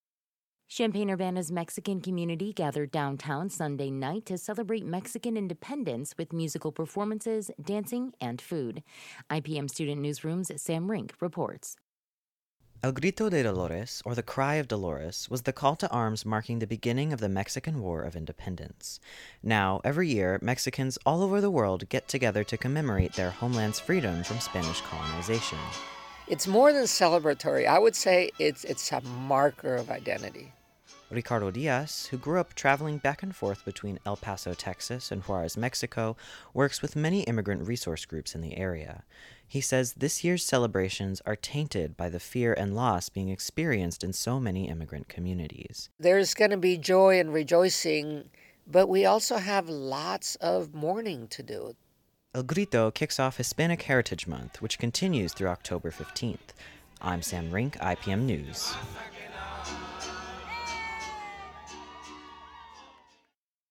The event featured musical and dance performances as well as local food trucks and vendors.